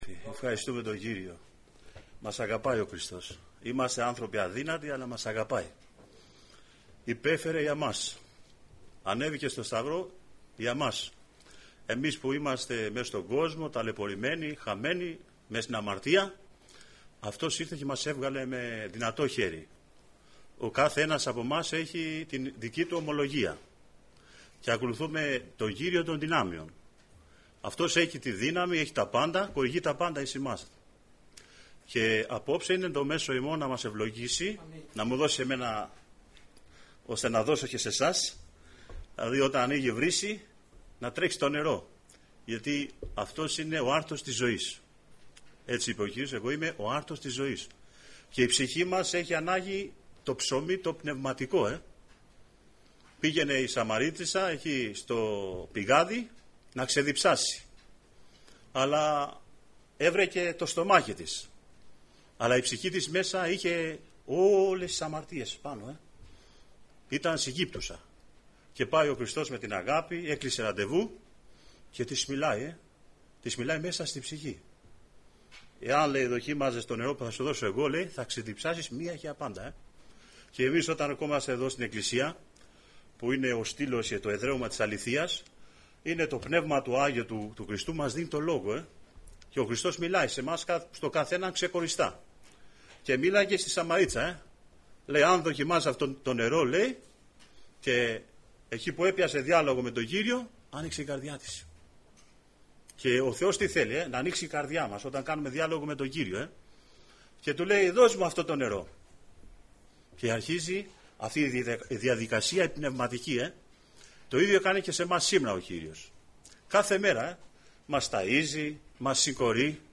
Νεολαία Αγίας Παρασκευής Λεπτομέρειες Σειρά: Κηρύγματα Ημερομηνία